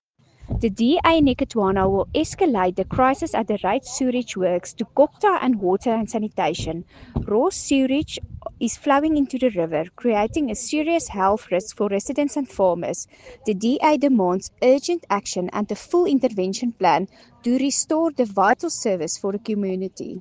Afrikaans soundbites by Cllr Anelia Smit and